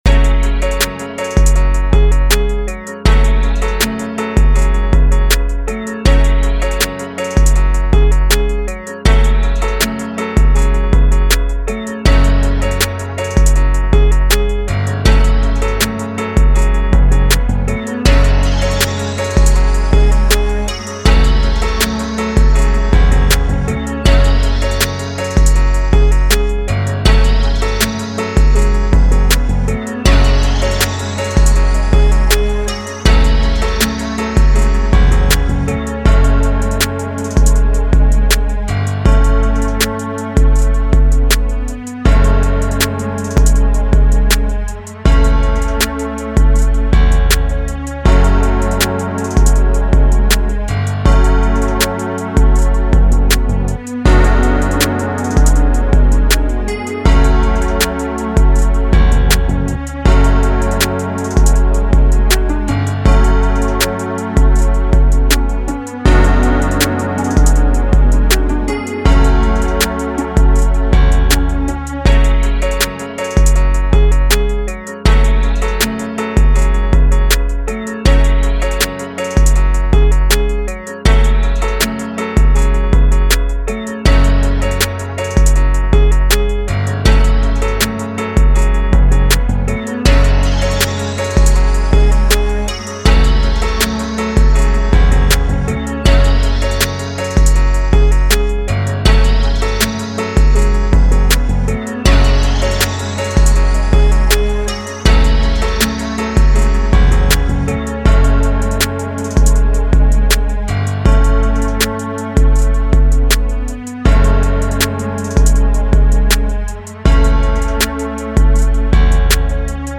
This is the official instrumental
Rap Instrumentals